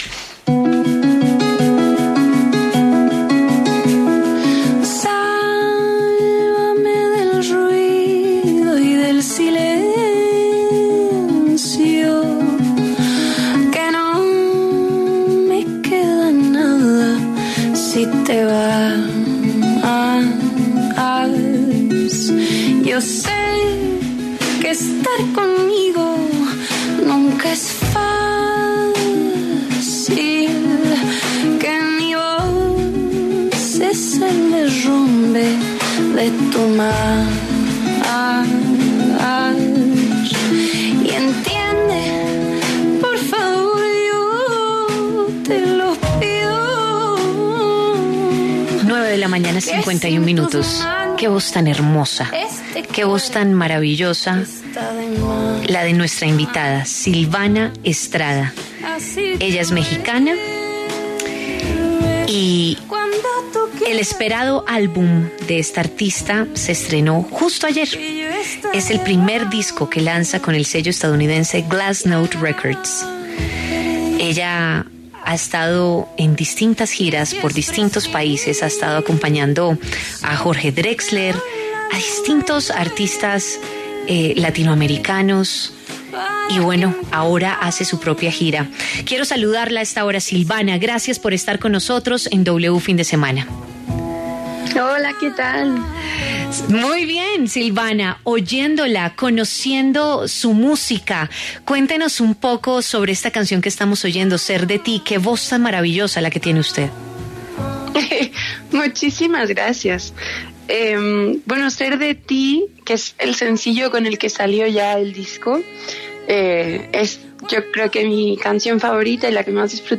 En diálogo con W Fin de Semana, la cantautora mexicana Silvana Estrada dio detalles de su próxima gira.